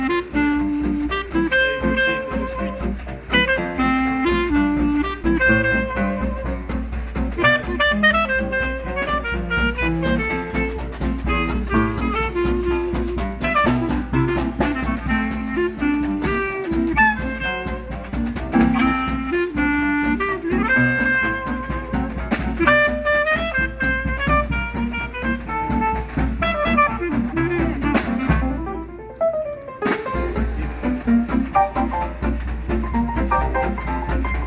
saxophone alto, clarinette
saxophone ténor, clarinette
piano
contrebasse
batterie.
jazz-swing